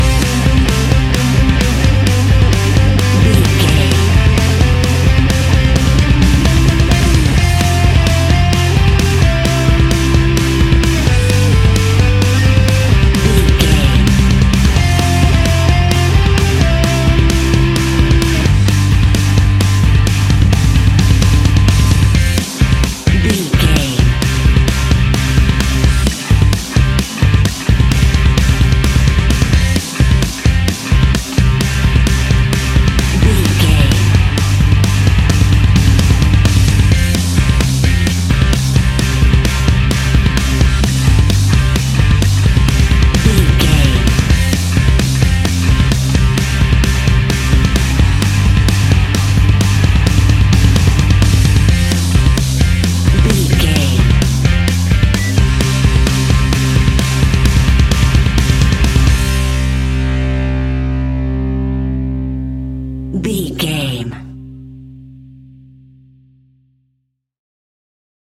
Epic / Action
Ionian/Major
hard rock
distortion
punk metal
instrumentals
Rock Bass
heavy drums
distorted guitars
hammond organ